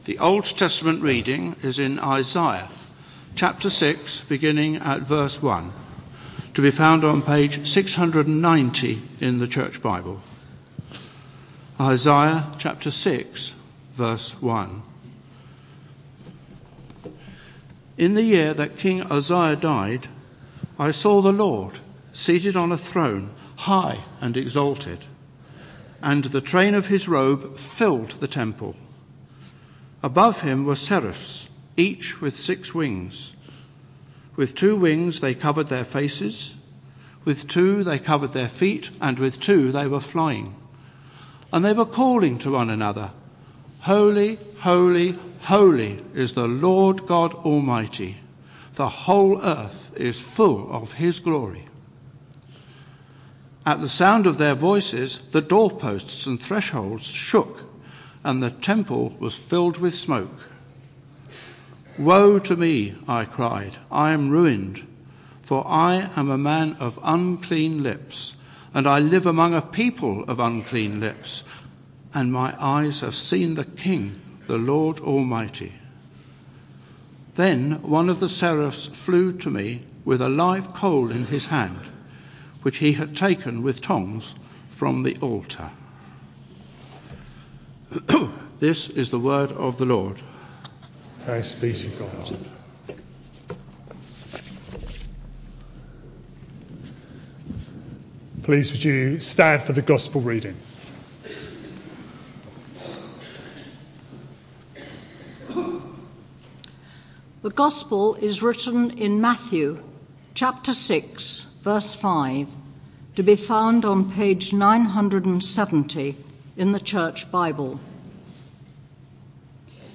This sermon is part of a series: 10 January 2016